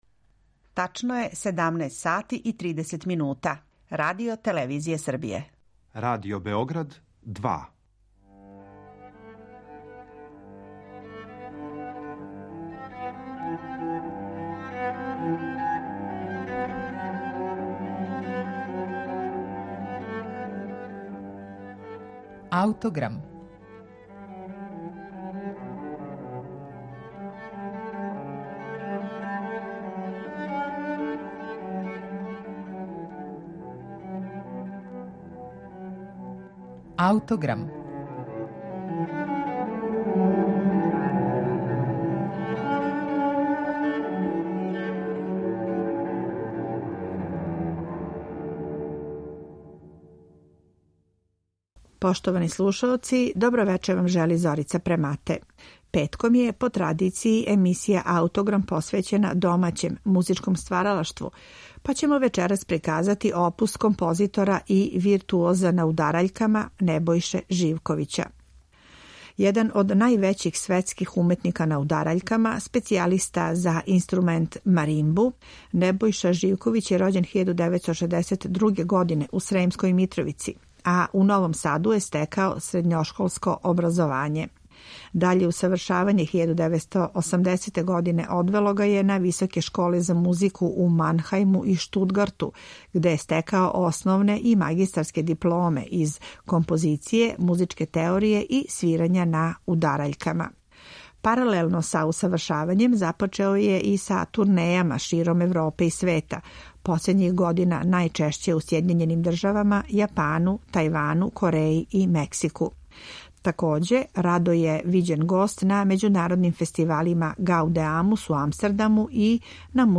Дела за маримбу